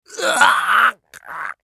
青年ボイス～ホラー系ボイス～
【痛がる1】